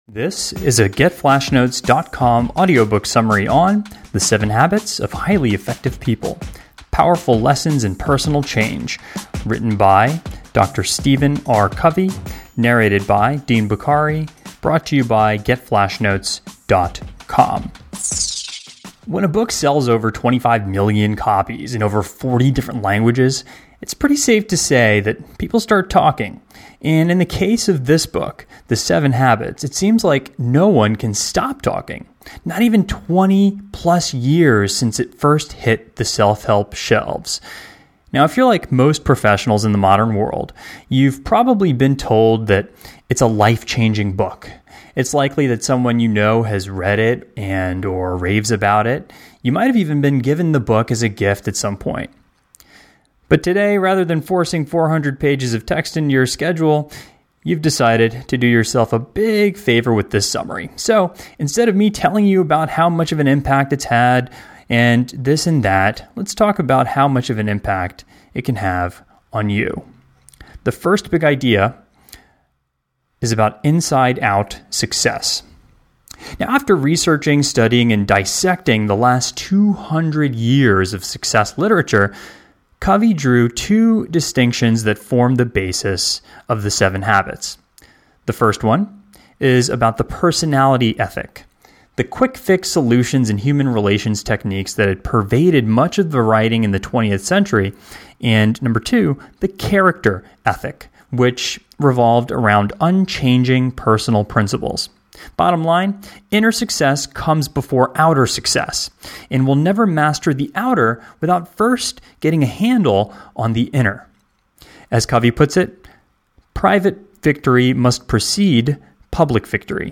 FN007-The-7-Habits-of-Highly-Effective-People-Audio-Summary.output.mp3